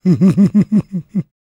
Index of /90_sSampleCDs/ILIO - Vocal Planet VOL-3 - Jazz & FX/Partition I/2 LAUGHS
PREACHIN124.wav